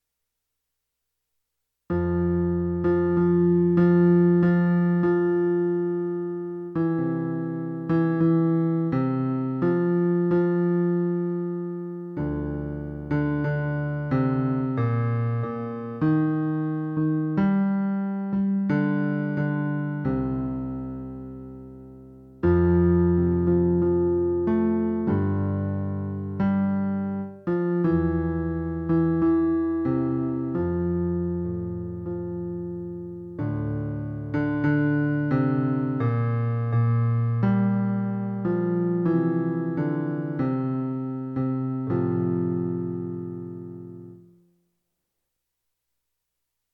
As I Have Done For You - Baritone